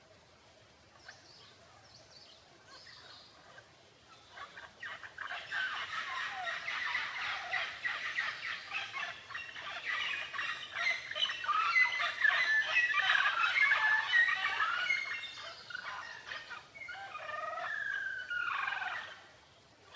Les ruines mayas de Tikal au milieu de la jungle valent aussi pour leur ambiance sonore.
pajaros3.mp3